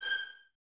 Index of /90_sSampleCDs/Miroslav Vitous - String Ensembles/23 Violins/23 VS Stacc